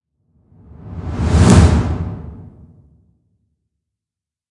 沙发上的撞击
描述：我再次在我的工作室放置了一些麦克风，并在我的沙发上录了一下。适用于任何形式的影响
Tag: 爆炸 炸弹 声音 爆炸 立管 射击 爆炸 繁荣 上升 爆炸 冲击波 沙发 电影 上升 低音 打击 冲击